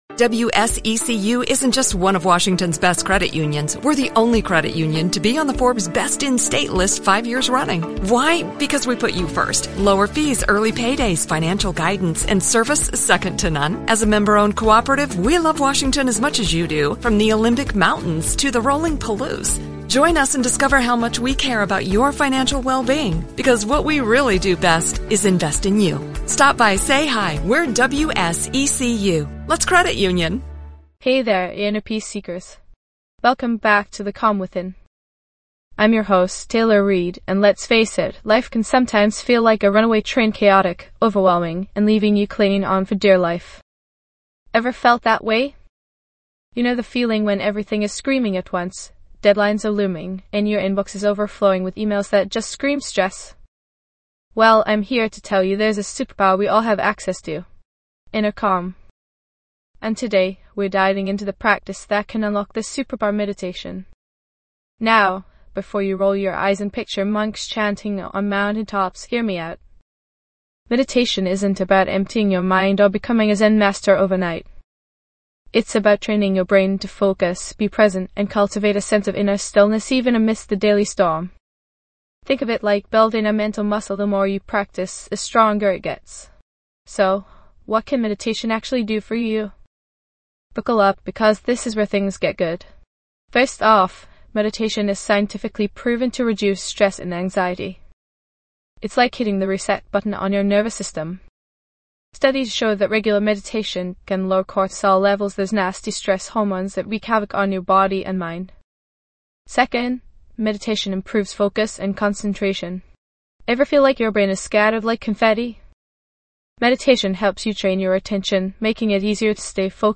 Discover the art of finding inner calm through a guided meditation practice.
This podcast is created with the help of advanced AI to deliver thoughtful affirmations and positive messages just for you.